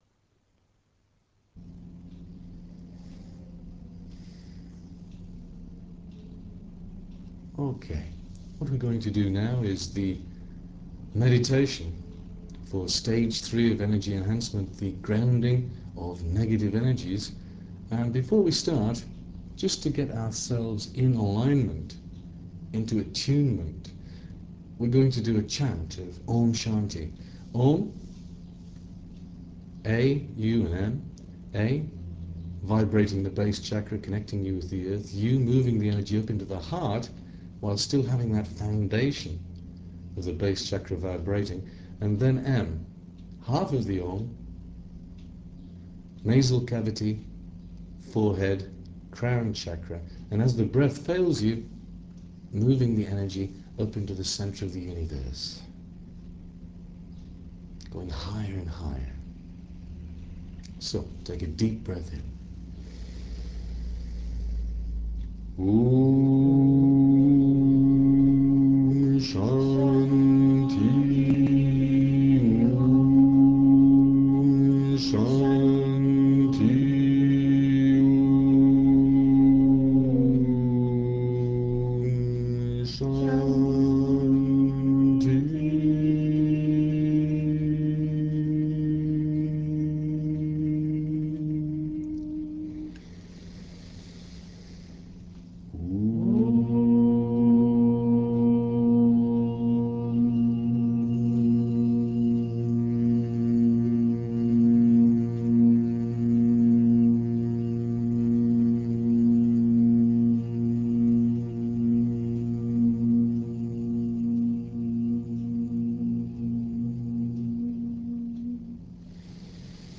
My talk is upon a removal of blockages in the Base Chakra, source of Your Kundalini Energy the hot fires of purification and transmutation and Foundation of the Energies of Your Enlightenment.